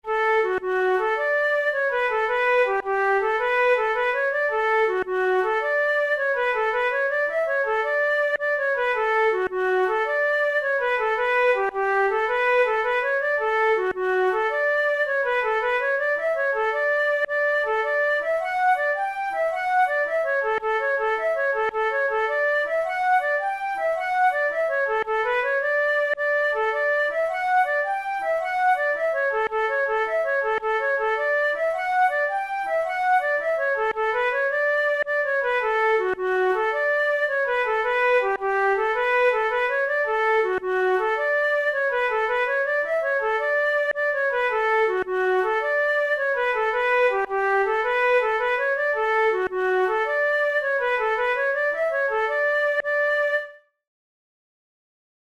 InstrumentationFlute solo
KeyD major
Time signature6/8
Tempo108 BPM
Jigs, Traditional/Folk
Traditional Irish jig
This lively jig appears to be unique to Francis O'Neill's collection The Dance Music of Ireland, published in Chicago in 1907.